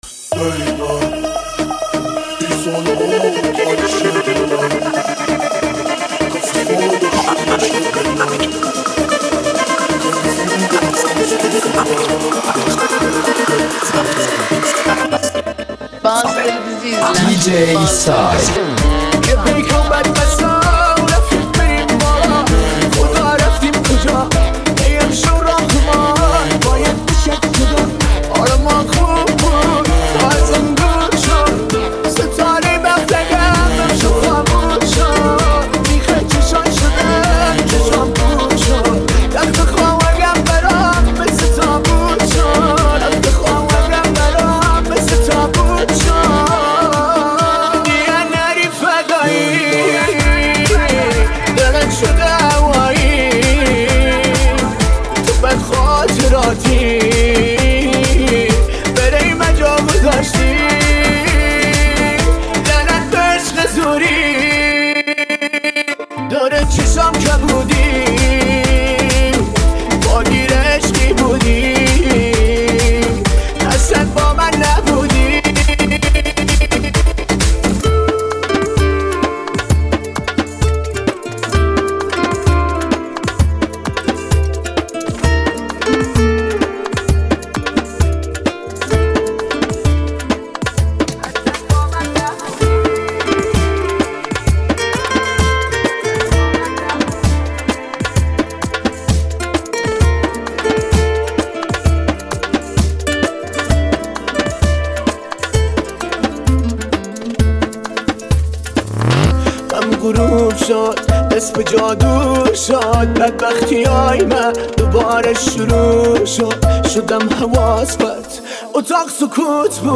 ریمیکس تند بیس دار